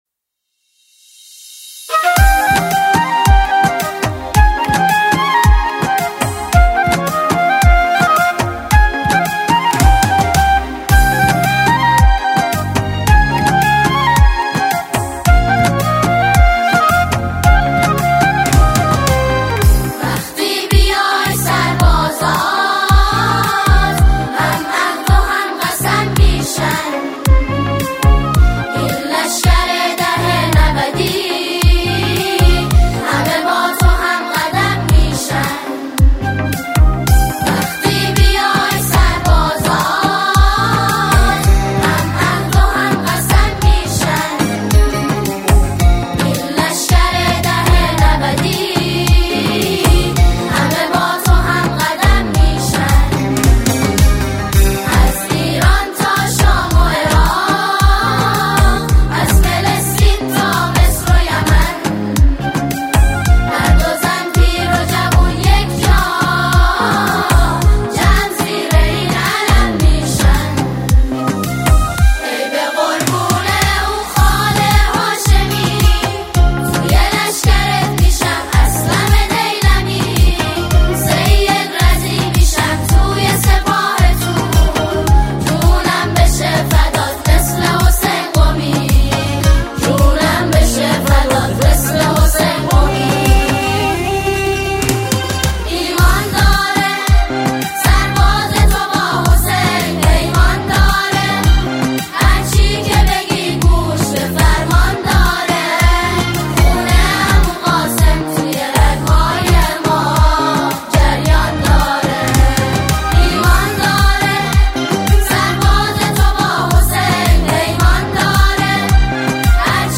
نماهنگ زیبای جدید